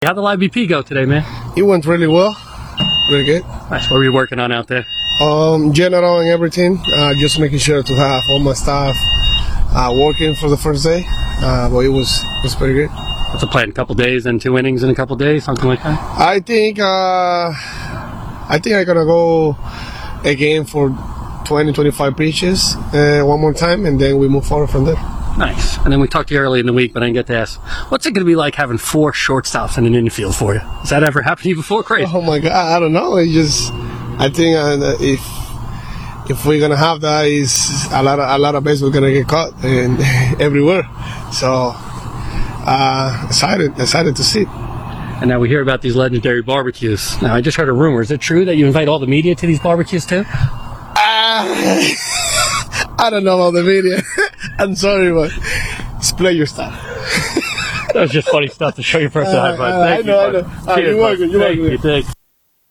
“Fue muy bien, muy bien”, dijo Peralta al ser cuestionado sobre la sesión por SNY TV.
“No sé nada de eso”, dijo entre risas antes de despedirse, cerrando una jornada positiva en su camino hacia el inicio de la campaña.